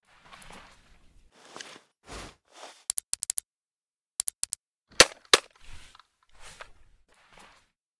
pda_vibros.ogg